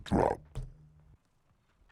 • demonic techno voice "drop".wav
Changing the pitch and transient for a studio recorded voice (recorded with Steinberg ST66), to sound demonic/robotic.